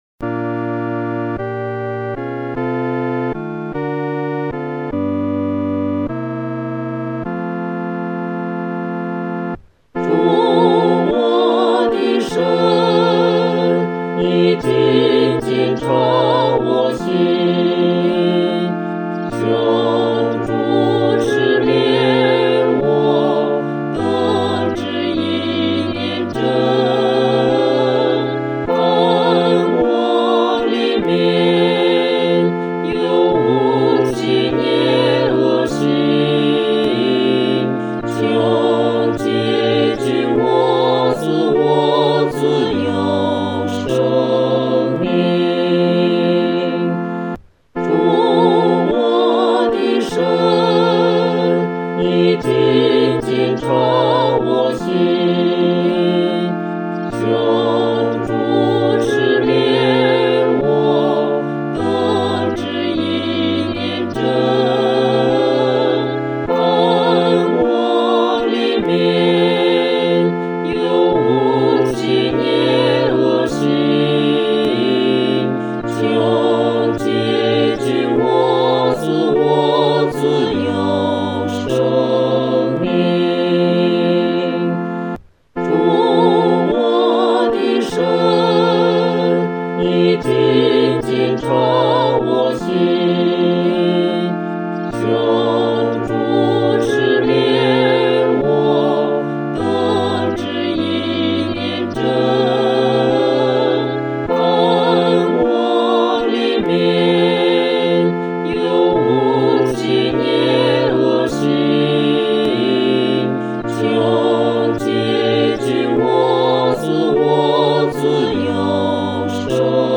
合唱
四声
这首圣诗速度不宜快，要预备敬虔、认罪的心来弹唱。